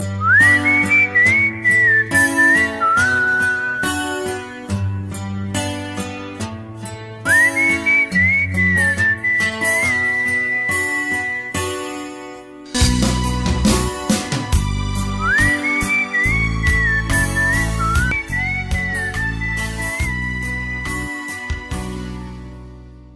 Valentine Tone